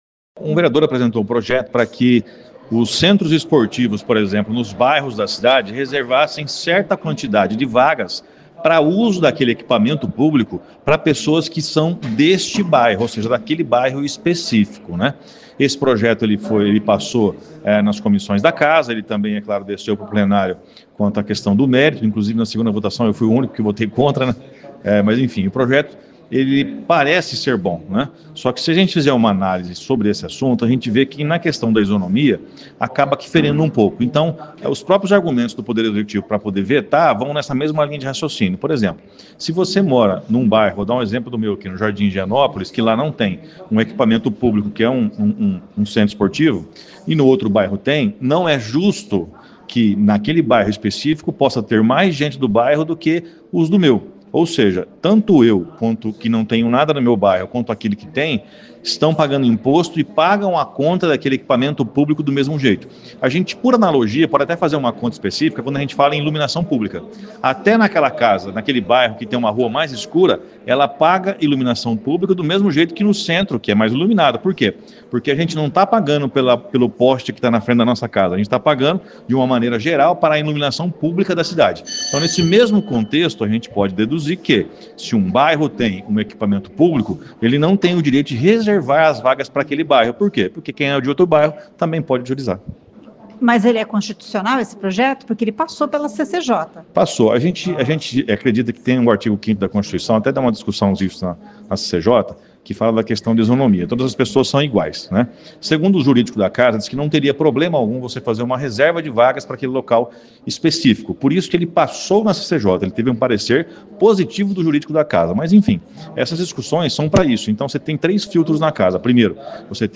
O presidente da CCJ, vereador Flávio Mantovani (PSD), explica os diferentes entendimentos da CCJ.